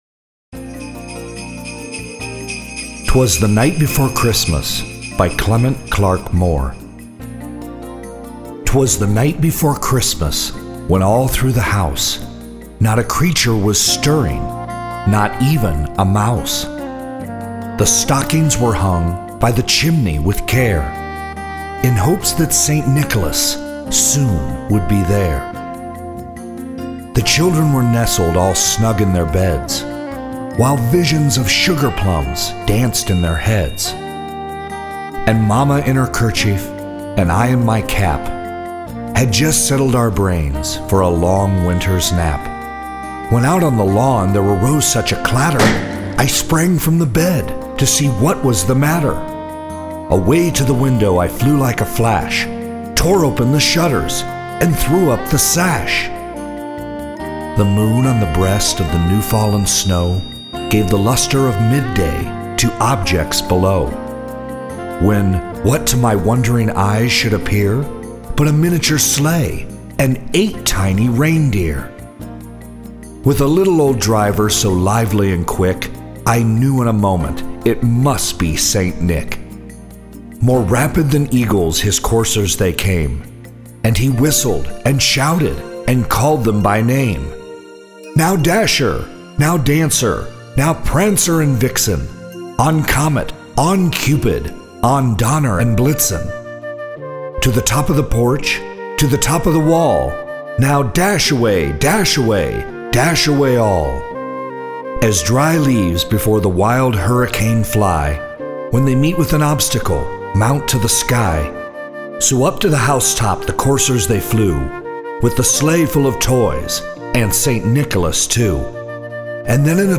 Reading 'Twas The Night Before Christmas